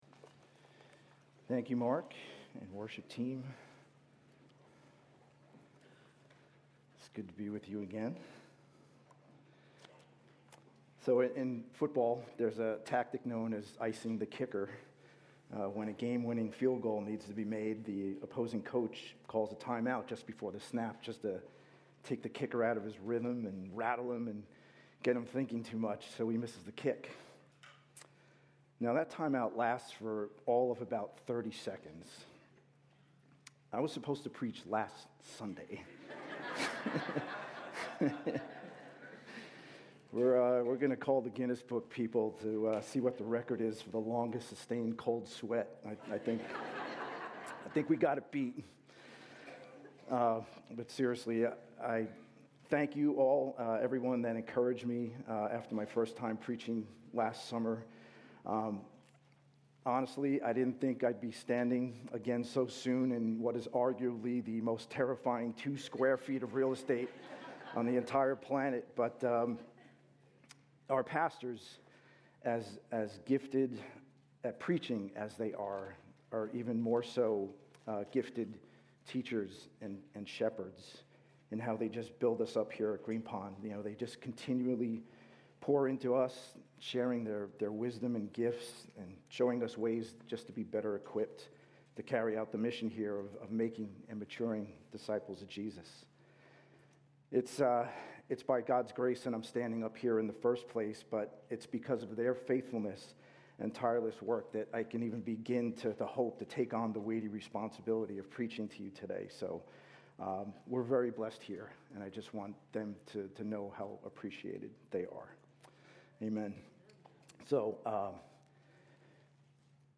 A message from the series "Ezra/Nehemiah." In Nehemiah 4:1-23, we learn that faithful believers bring the battle before the Lord, that God is bigger than our enemies, and we should fearlessly work because God faithfully fights for us.